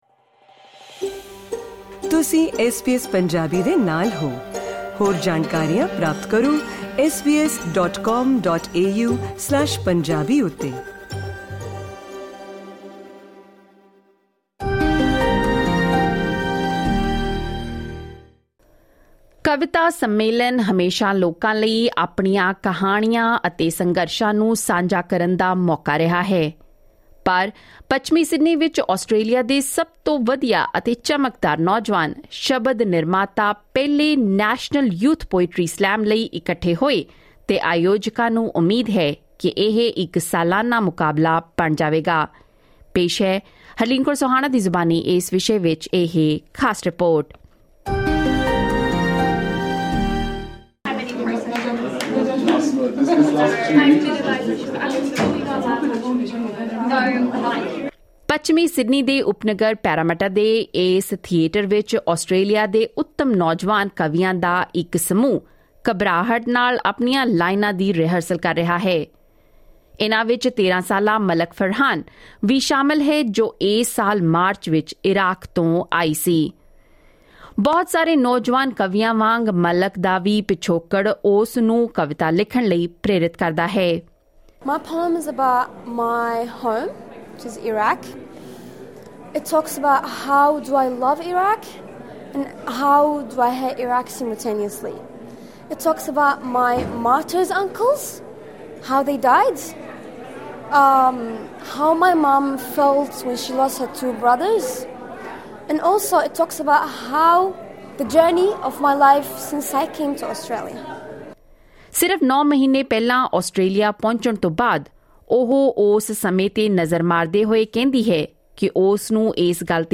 As the event got underway, it was clear the performance poetry impressed the crowd in attendance, who clicked their fingers in approval.